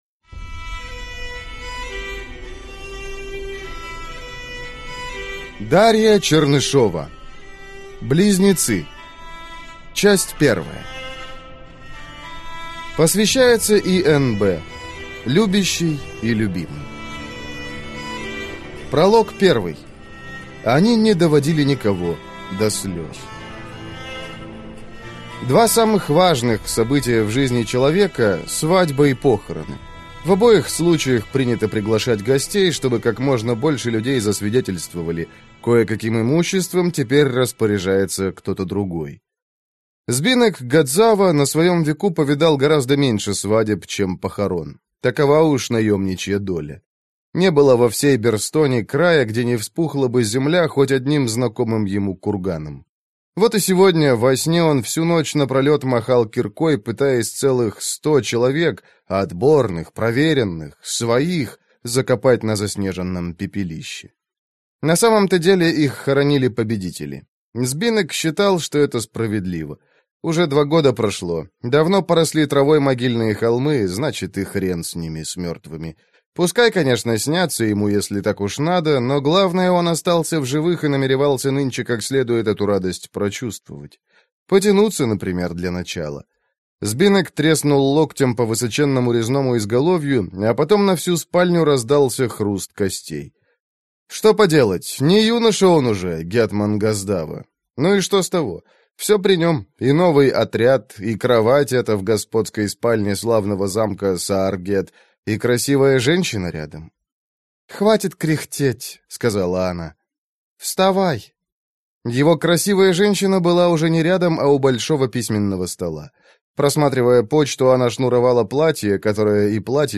Аудиокнига Близнецы. Часть первая | Библиотека аудиокниг